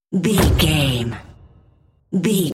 Dramatic hit slam door
Sound Effects
heavy
intense
dark
aggressive